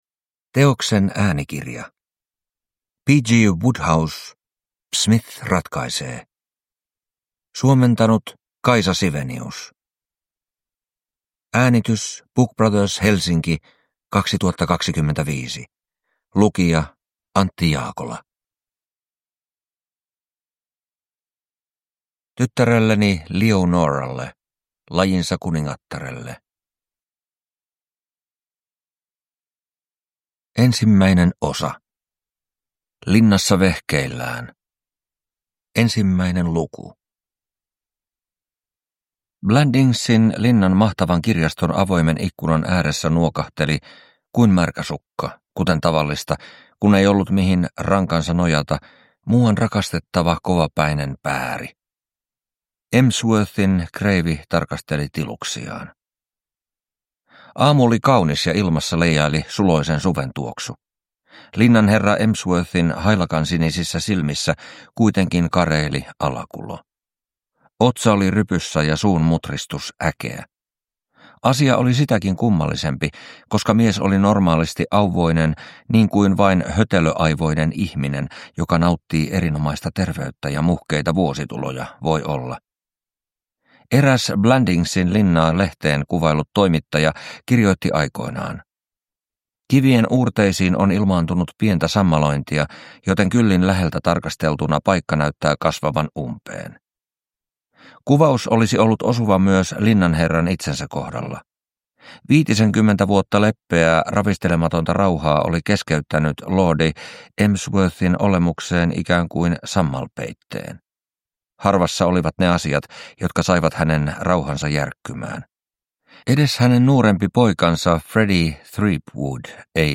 Psmith ratkaisee – Ljudbok